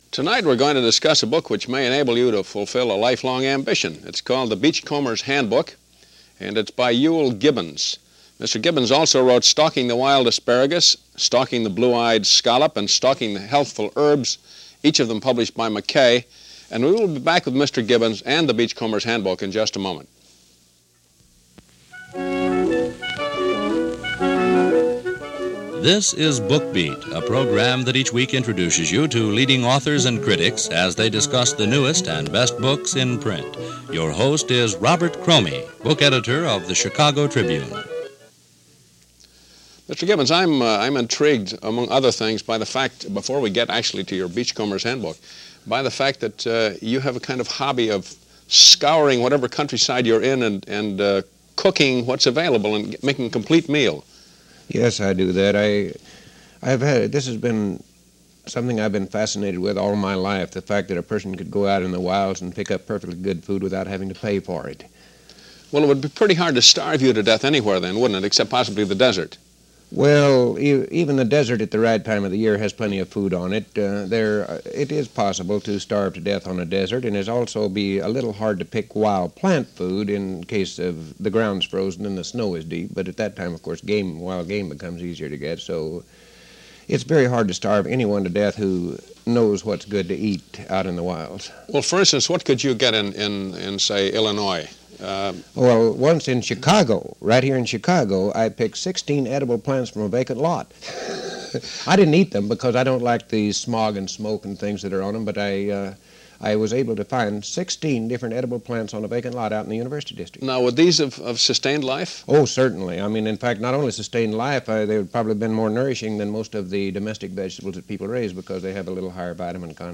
He did once, as he does in this interview, admit he found some 16 varieties of edible vegetation in a vacant neighborhood lot – although he also confessed to not taking advantage of it, he did say it was possible.
This interview, from the Book Beat series, comes from 1967 and he is busy promoting his then-latest Beachcombers Handbook, which extolled the virtues of edibles found along the average seashore.